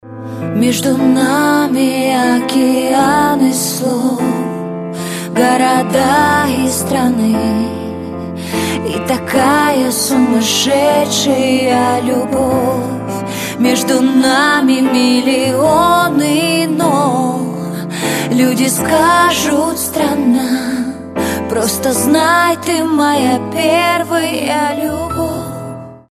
• Качество: 256, Stereo
поп
женский вокал
live